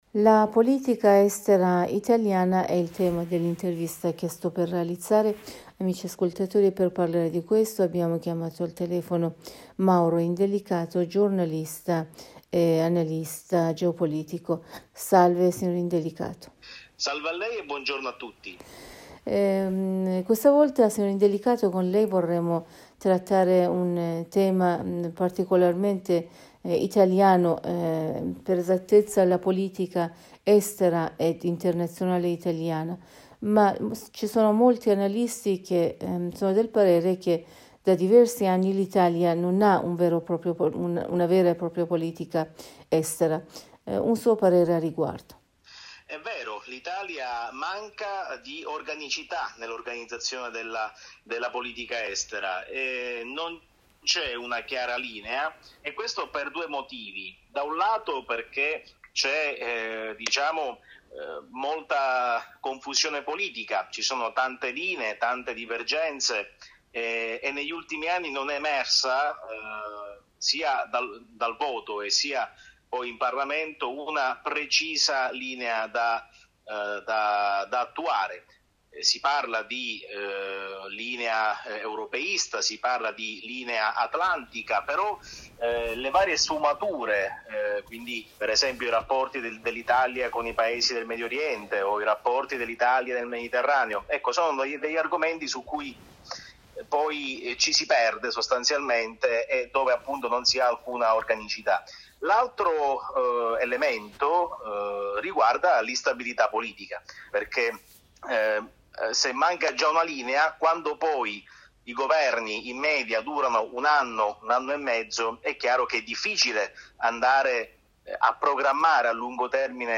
in un collegamento telefonico